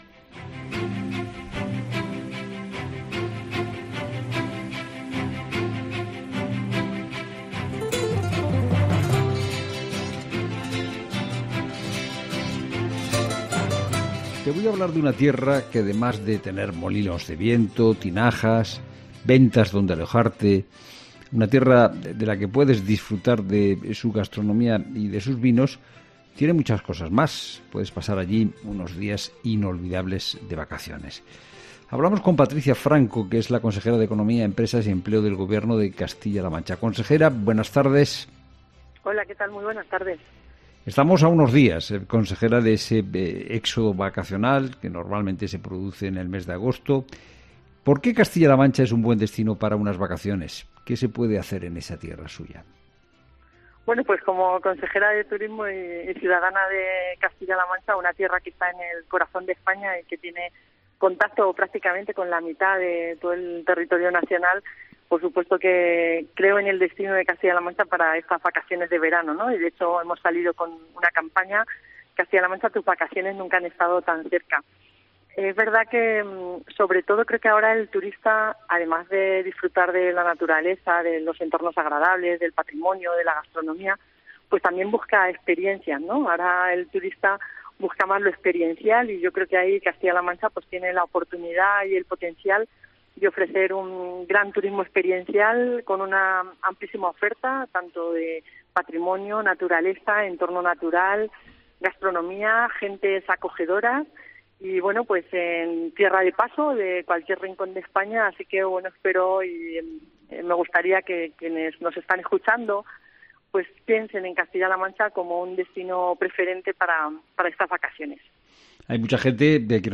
A unos días del éxodo vacacional en tiempos de COVID, Patricia Franco, que es la Consejera de Economía, Empresas y Empleo del Gobierno de Castilla-La Manchanos nos ha contado en 'La Tarde' de COPE qué se puede hacer en esta preciosa tierra y por qué cree en esta autonomía: “Tiene contacto con la mitad de todo el territorio nacional, creo en el destino de Castilla-La Mancha con la campaña que hemos creado”.